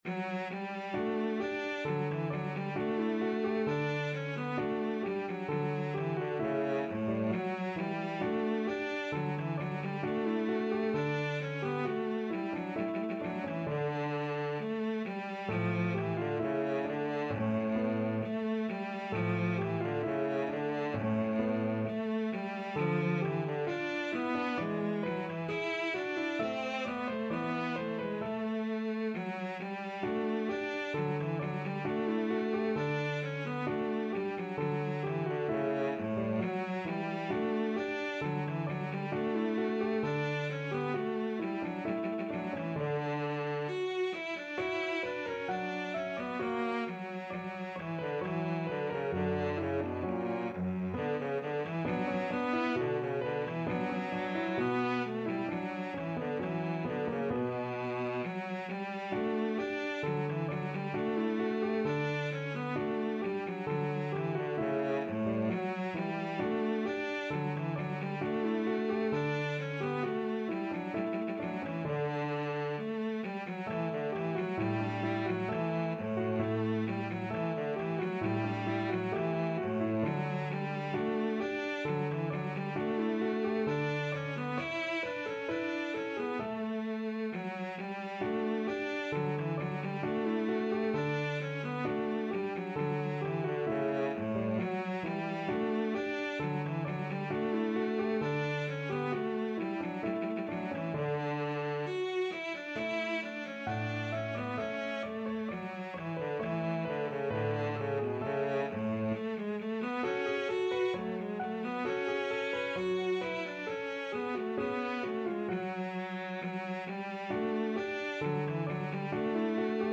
Cello
D major (Sounding Pitch) (View more D major Music for Cello )
2/2 (View more 2/2 Music)
~ = 100 Allegretto =c.66
Classical (View more Classical Cello Music)